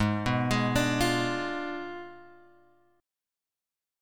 G# Diminished 7th